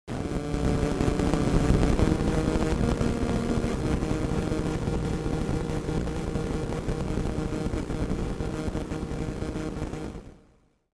دانلود آهنگ رادیو 4 از افکت صوتی اشیاء
جلوه های صوتی
دانلود صدای رادیو 4 از ساعد نیوز با لینک مستقیم و کیفیت بالا